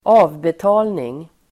Uttal: [²'a:vbeta:lning]